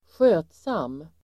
Ladda ner uttalet
Uttal: [²sj'ö:tsam:]